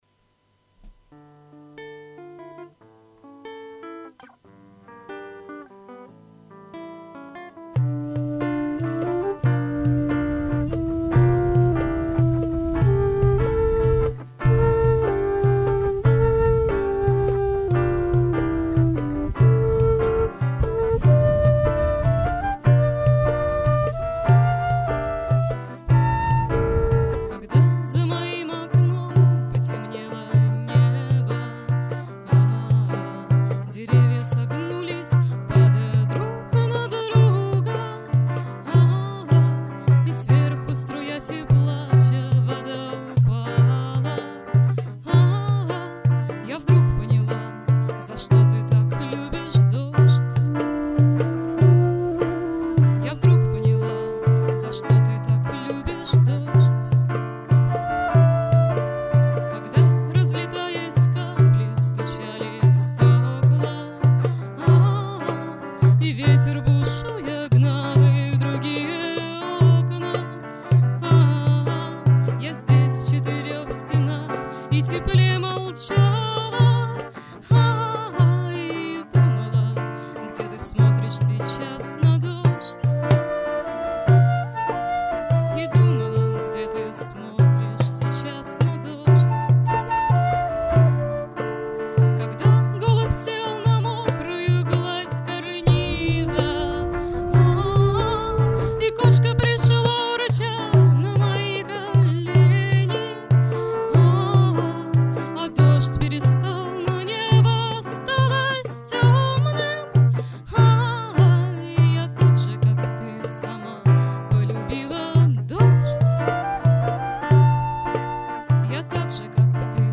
запись с репетиции 25 февраля 2007